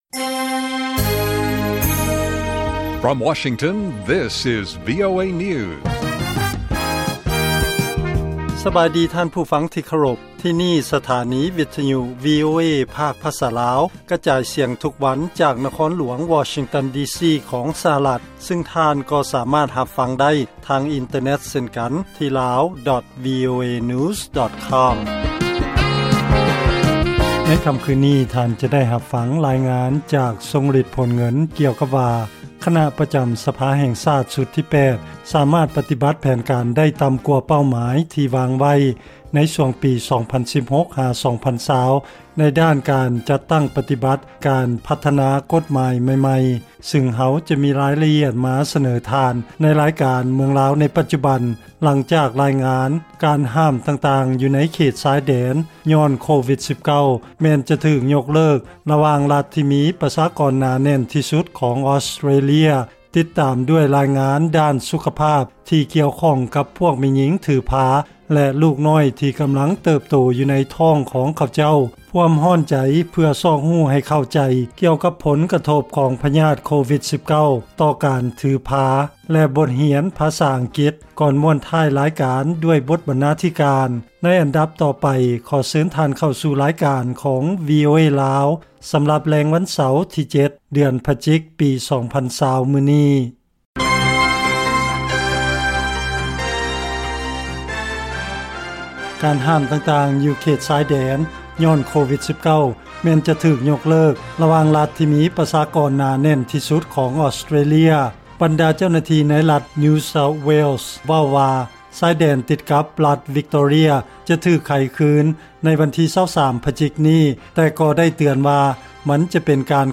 ລາຍການກະຈາຍສຽງຂອງວີໂອເອ ລາວ
ວີໂອເອພາກພາສາລາວ ກະຈາຍສຽງທຸກໆວັນ.